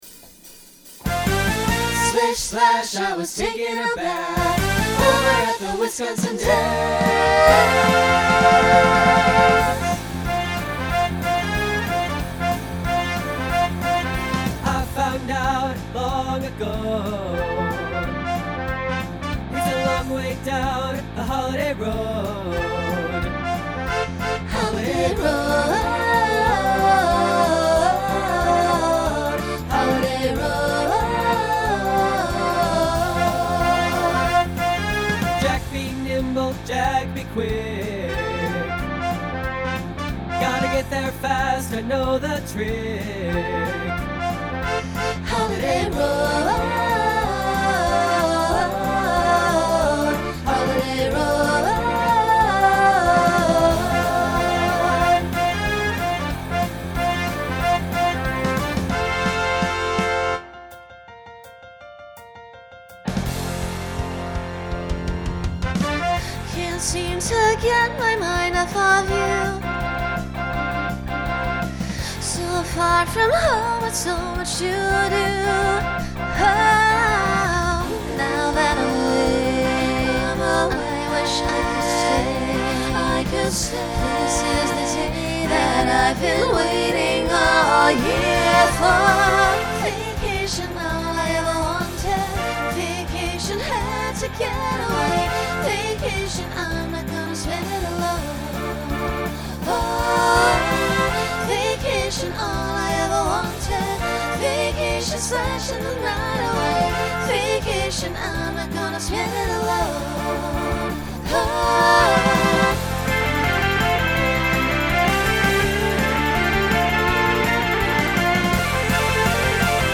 Genre Rock Instrumental combo
Voicing Mixed